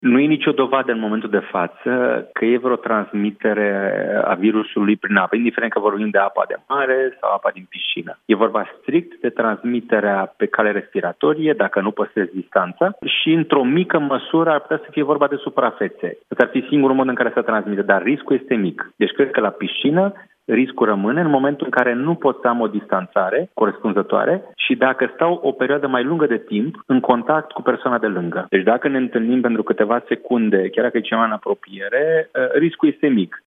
Medic infecționist: Nu sunt dovezi că virusul se transmite prin apă, fie ea din mare sau din piscine | AUDIO